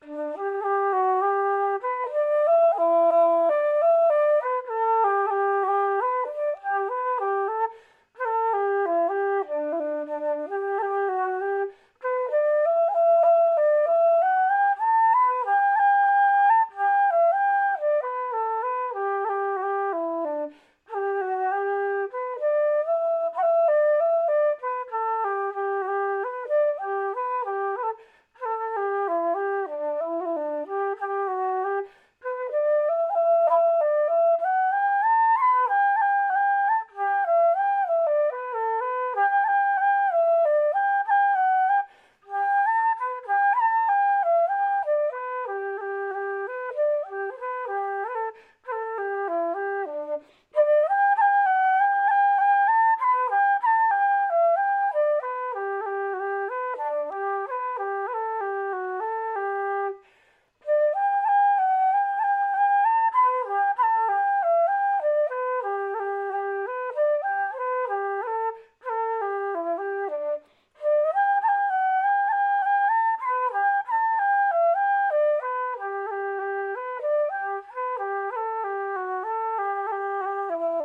set de 2 jigs
Gaelic Club » au violon